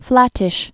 (flătĭsh)